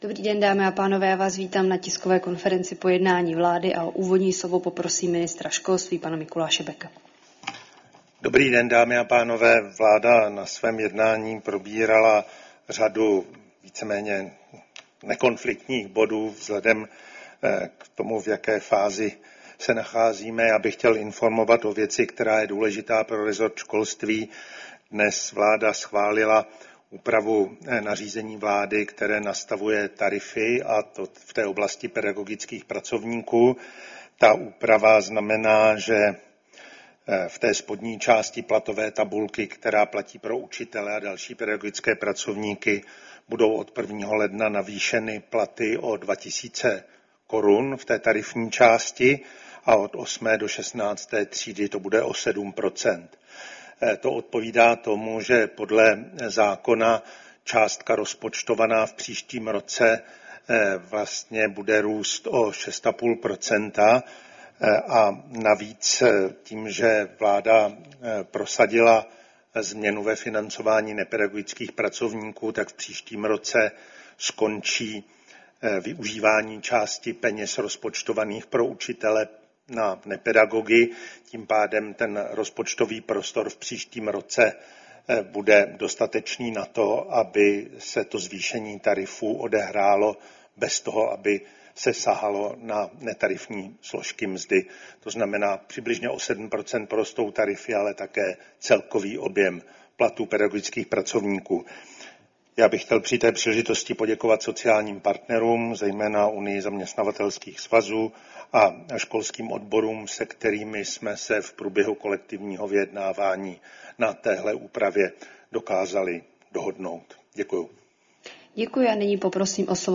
Tisková konference po jednání vlády, 29. října 2025